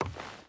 Footsteps / Wood
Wood Walk 2.wav